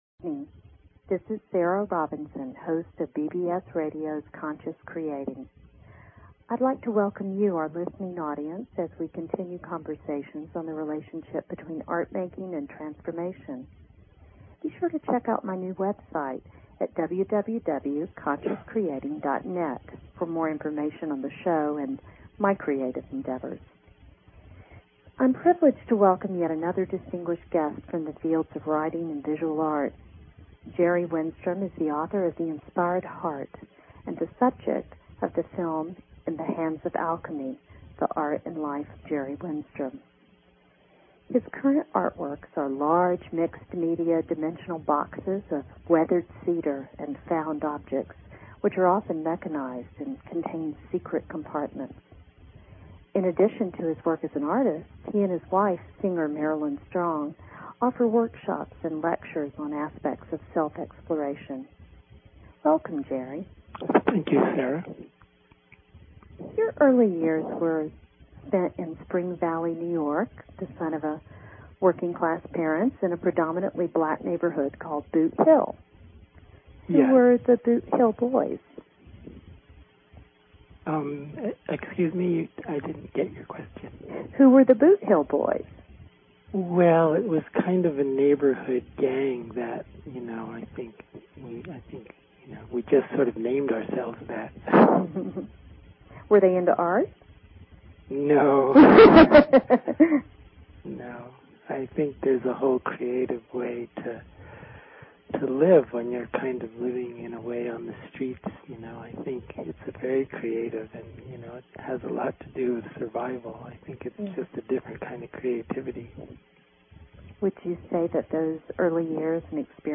Talk Show Episode, Audio Podcast, Conscious Creating and Courtesy of BBS Radio on , show guests , about , categorized as